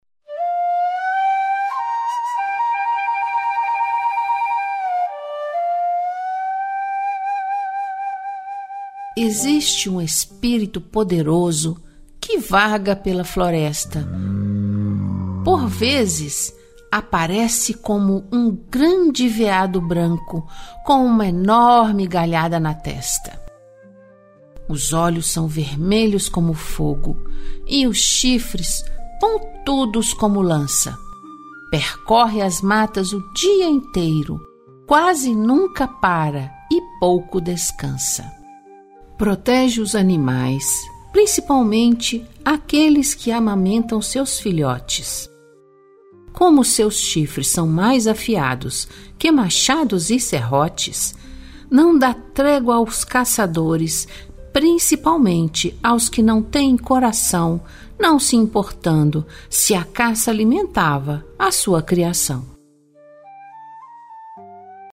Audiolivro - A lenda de Anhangá: recontada em versos